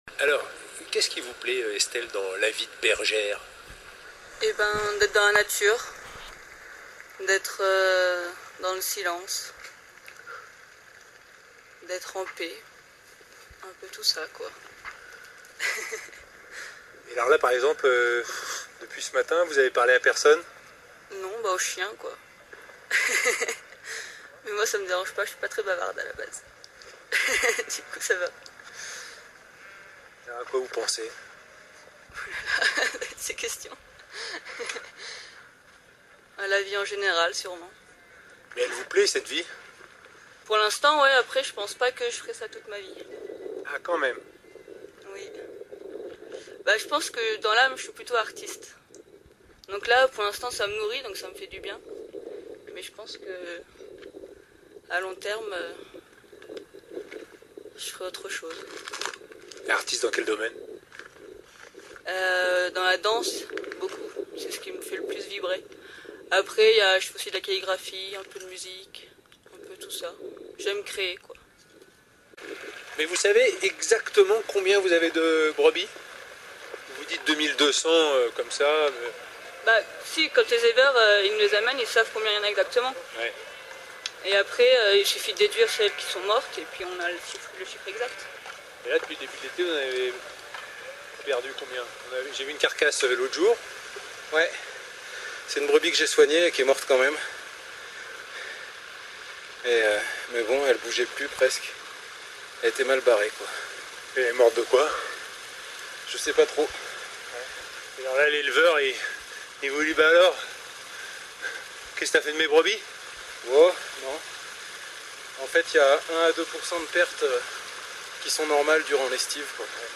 Ariège, été 2011
Partons en compagnie… d’un berger et d’une bergère.
On entend juste: « Qu’est-ce t’as fait? » C’est très fréquent à l’oral, quand on ne surveille pas spécialement sa façon de parler. On mange les mots et les syllabes.